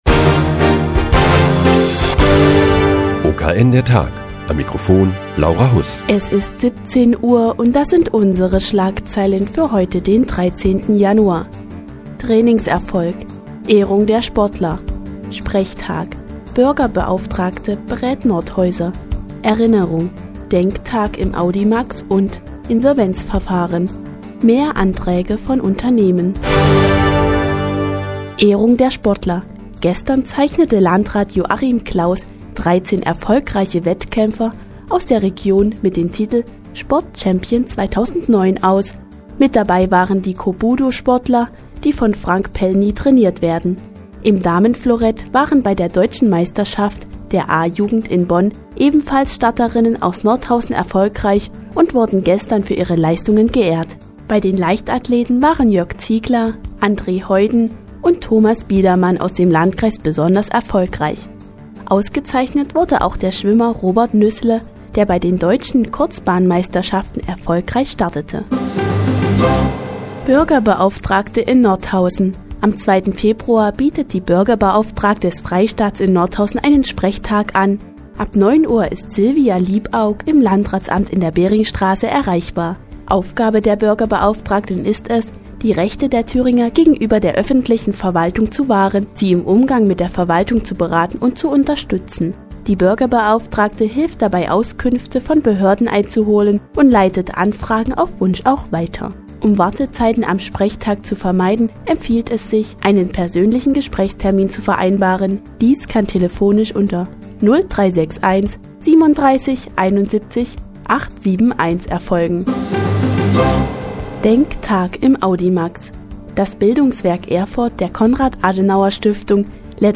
Die tägliche Nachrichtensendung des OKN ist nun auch in der nnz zu hören. Heute geht es um den "Sportchampion 2009" und den Sprechtag der Bürgerbeauftragten in Nordhausen.